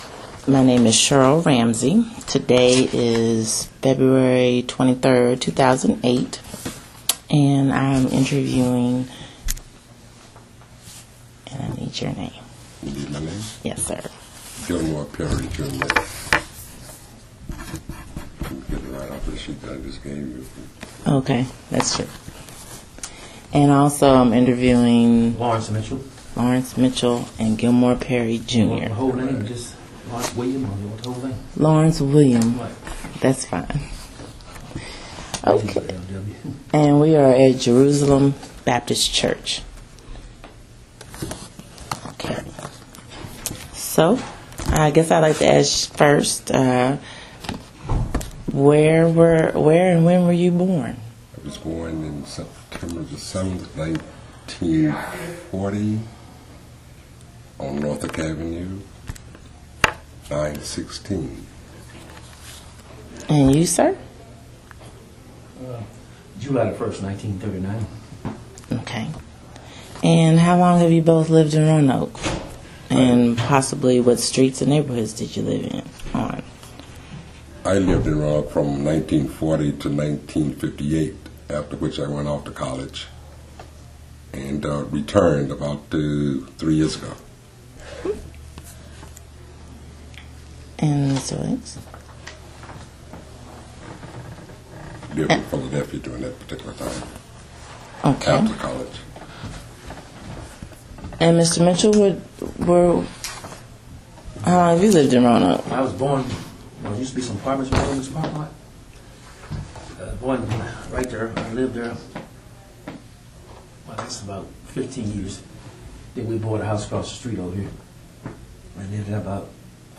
Neighborhood History Interview
Location: Jerusalem Baptist Church